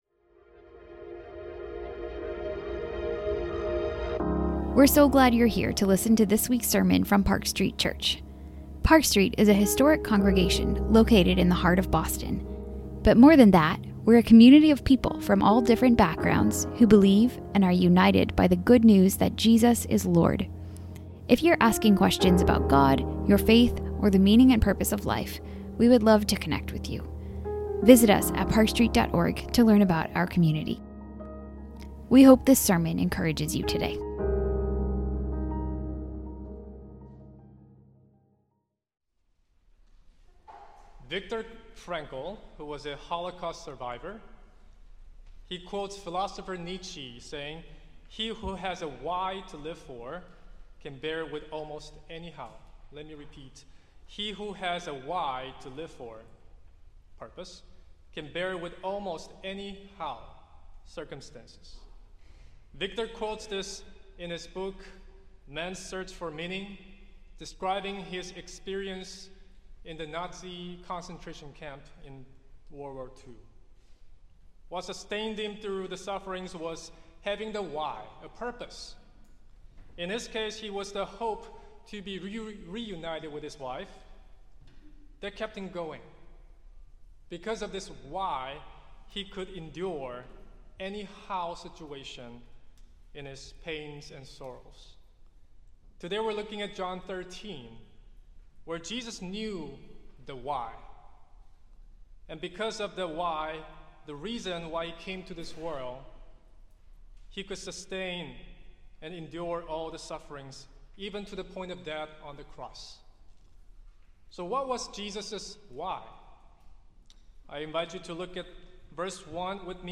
Sermons - Park Street Church